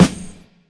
Sfggg_snr.wav